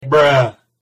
Japanese brue